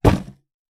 Punching Box Intense F.wav